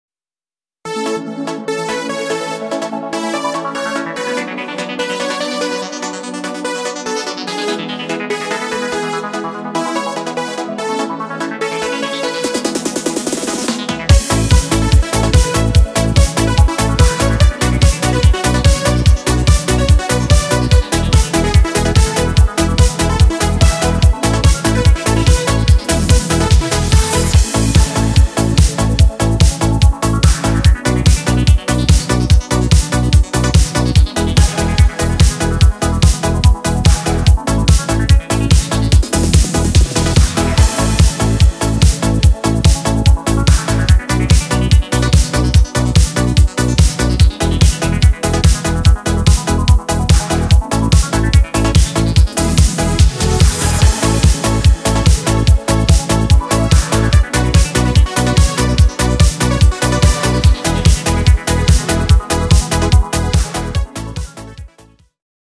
Taneczna propozycja
podkład dla wokalistów
Disco Polo